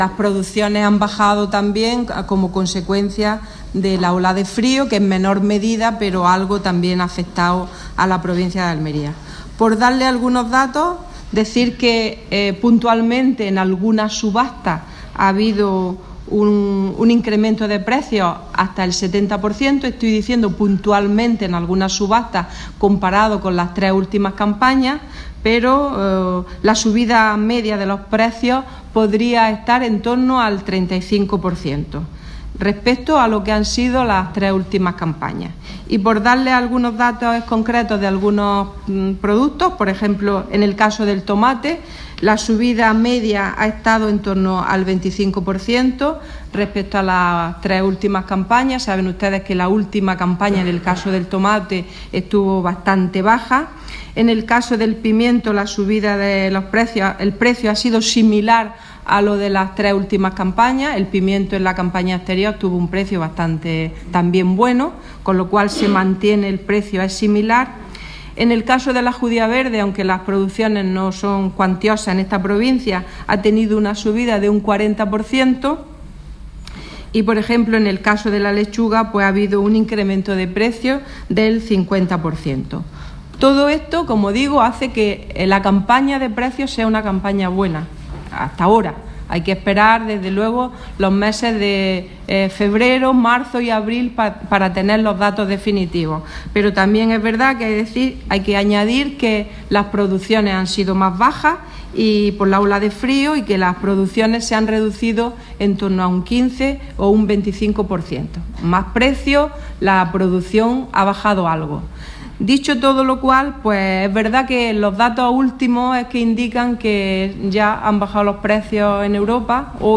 Declaraciones de Carmen Ortiz sobre precios de productos hortofrutícolas